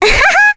Requina-vox-Happy1.wav